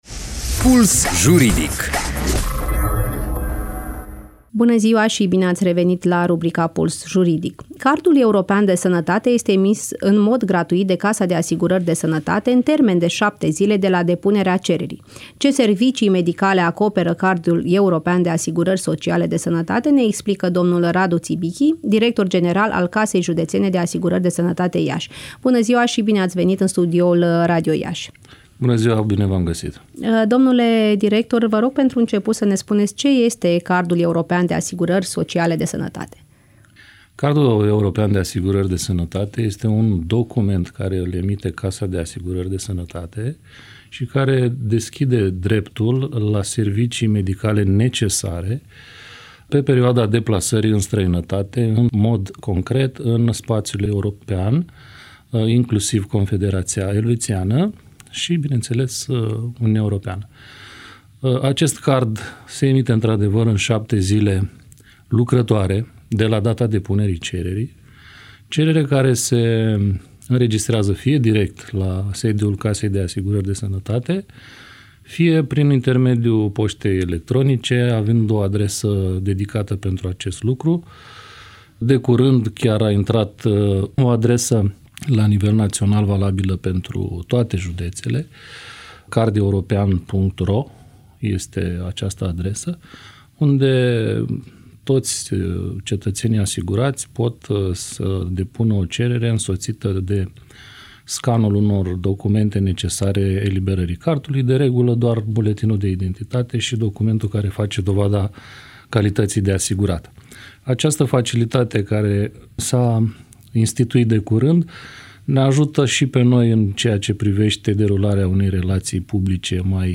Cardul european de sănătate este emis în mod gratuit de Casa de asigurări de sănătate, în termen de 7 zile de la depunerea cererii. Ce servicii medicale acoperă cardul european de asigurări sociale de sănătate ne explică Radu Țibichi, director general al Casei Județene de Asigurări de Sănătate Iași.